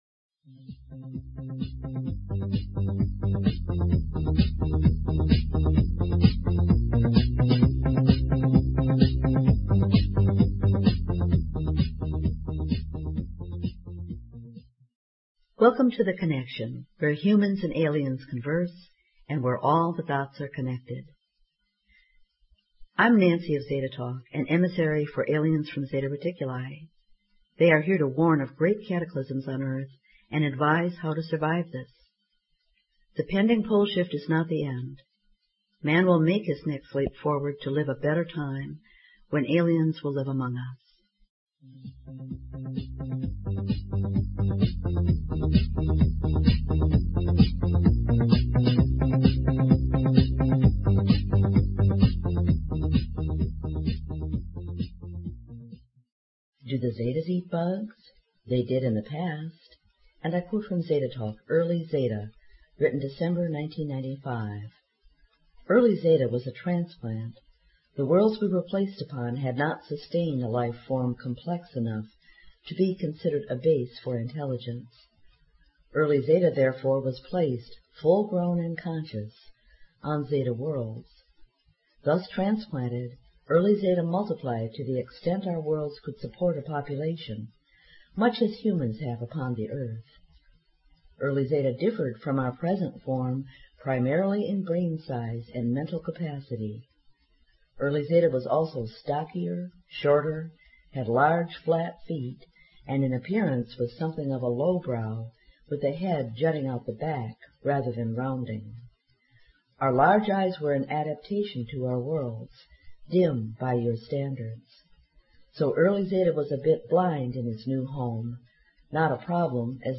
Talk Show Episode, Audio Podcast, The_Connection and Courtesy of BBS Radio on , show guests , about , categorized as